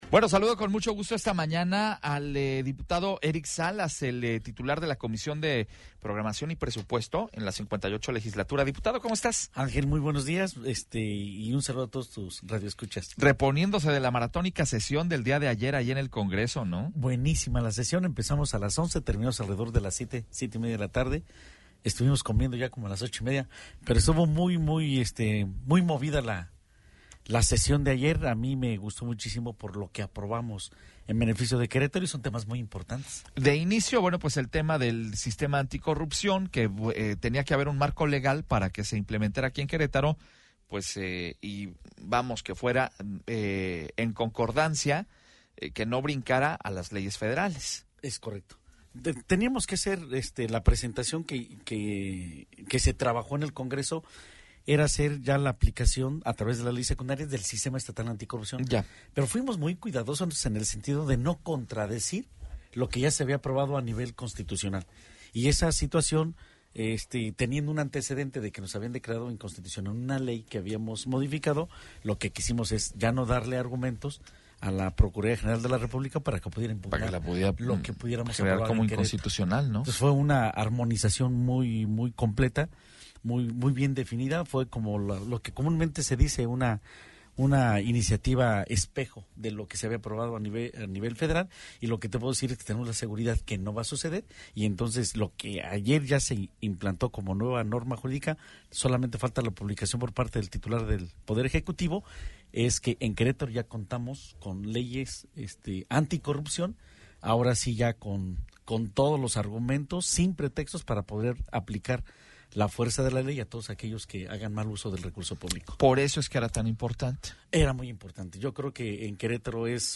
El diputado Eric Salas nos habla sobre las actividades legislativas de la semana - RR Noticias
Entrevistas